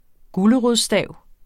Udtale [ ˈguləʁoðs- ]